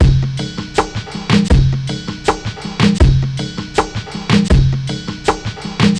Index of /90_sSampleCDs/Zero-G - Total Drum Bass/Drumloops - 1/track 04 (160bpm)